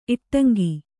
♪ iṭṭaŋgi